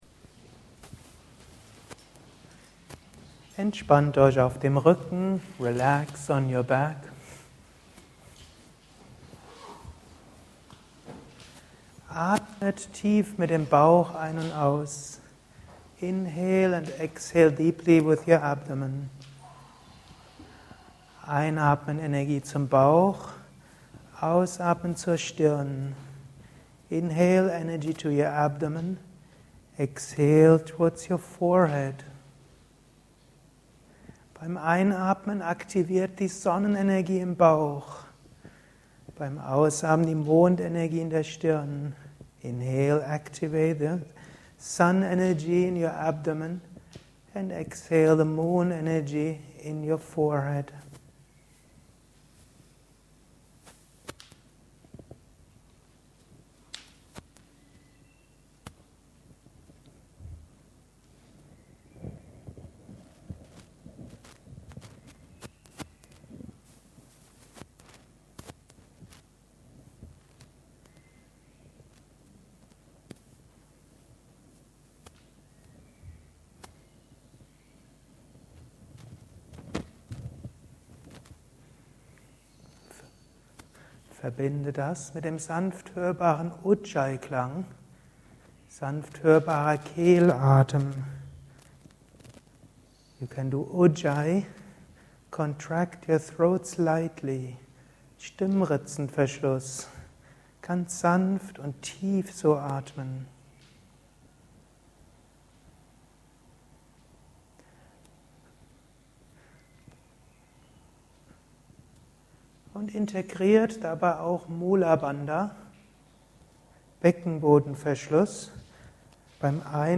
Eine fortgeschrittene Yogastunde deutsch-englisch. So kannst du auch die englischen Ansagen für eine Yogastunde lernen. Mit Bhramari, Sitali, Asanas mit Chakra-Konzentration, Bodyscan Entspannung.